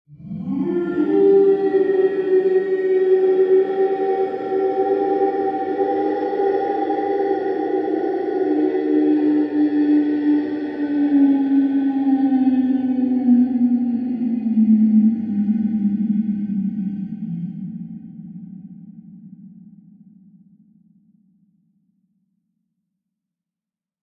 Creatures Voice Sound Effects Copyright sound effects free download